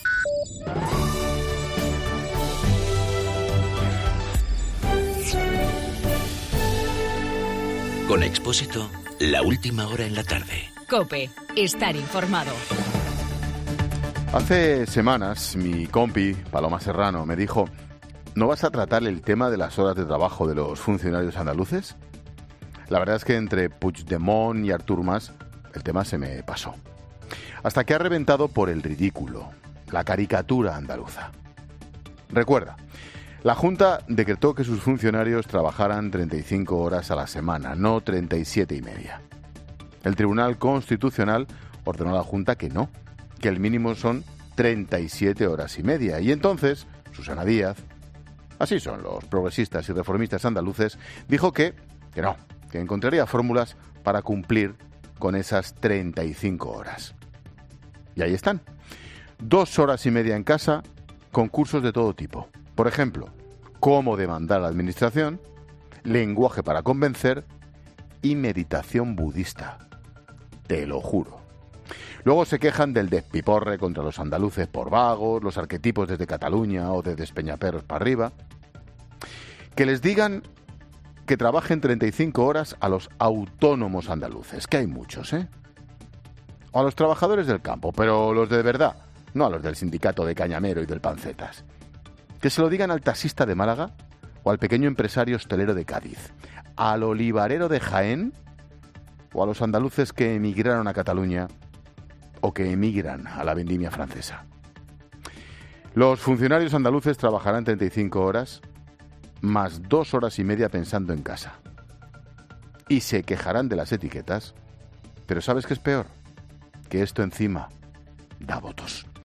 Monólogo de Expósito Expósito a las 17h: "Y ahí está.